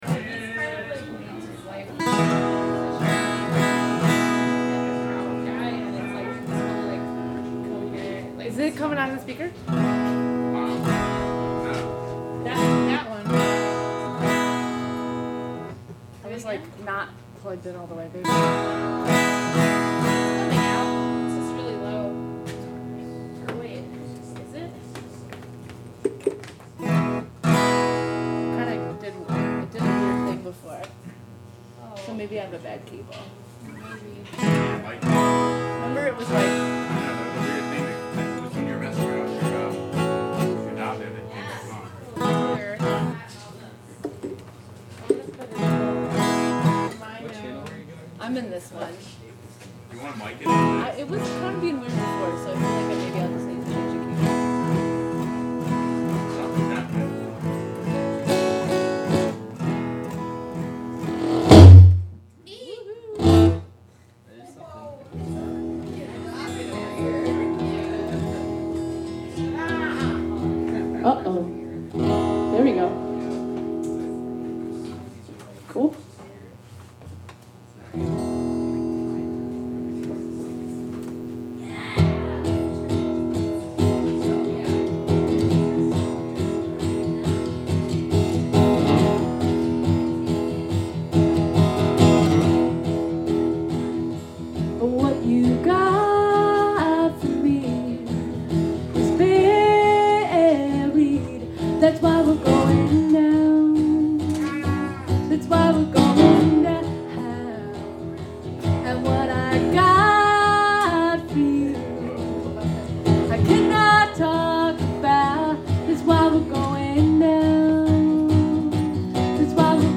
The Spotty Dog Books & Ale Recorded from live Wave Farm/WGXC webstream.